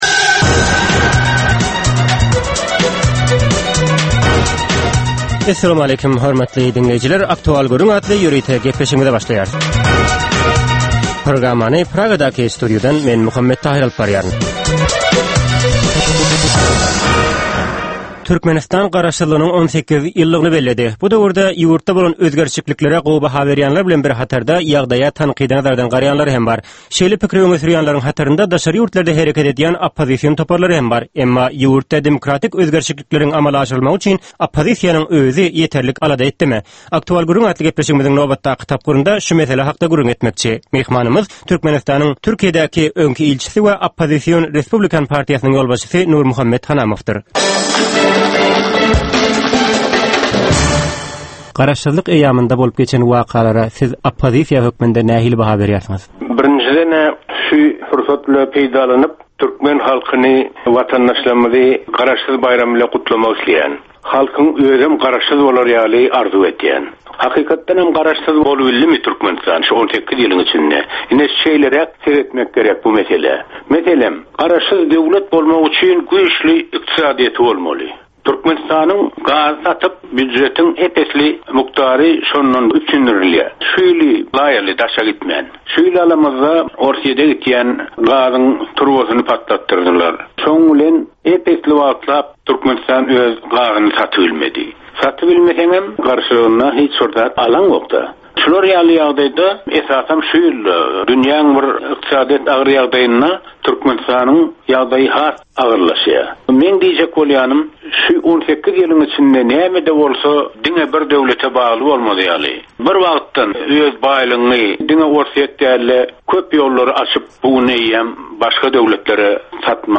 Hepdäniň dowamynda Türkmenistanda ýa-da halkara arenasynda ýüze çykan, bolup geçen möhüm wakalar, meseleler barada anyk bir bilermen ýa-da synçy bilen geçirilýän 10 minutlyk ýörite söhbetdeşlik. Bu söhbetdeşlikde anyk bir waka ýa-da mesele barada synçy ýa-da bilermen bilen aktual gürrüňdeşlik geçirilýär we meseläniň dürli ugurlary barada pikir alyşylýar.